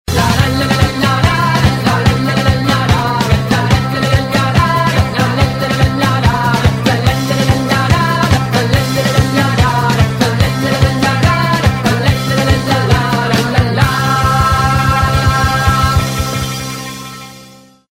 • Качество: 128, Stereo
веселые
рок